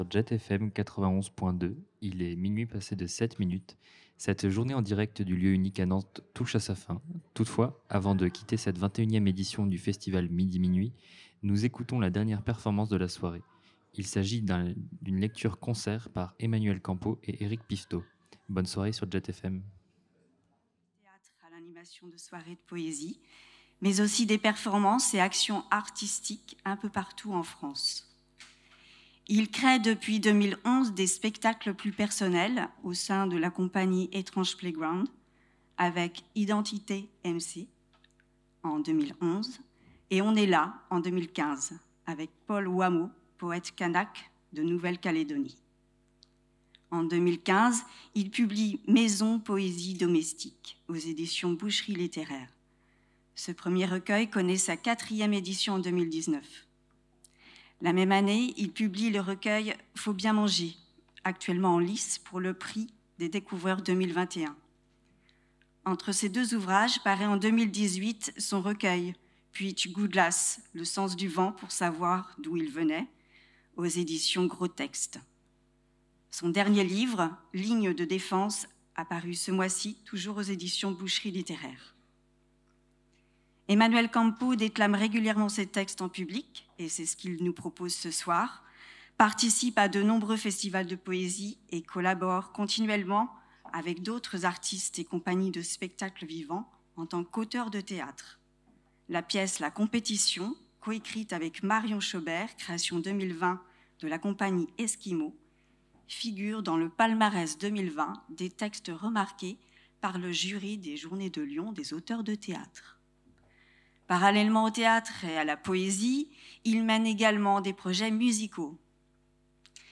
Un marathon poétique et radiophonique de plus de douze heures, en direct depuis le Lieu Unique.